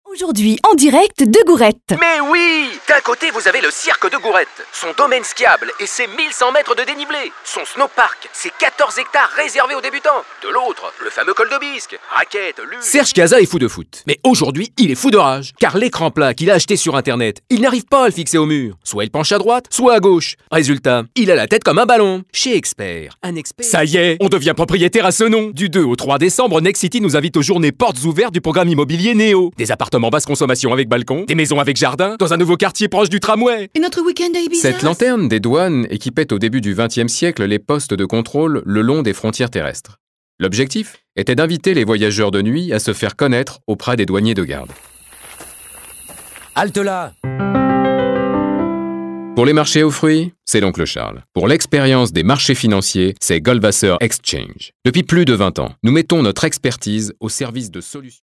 voix pubs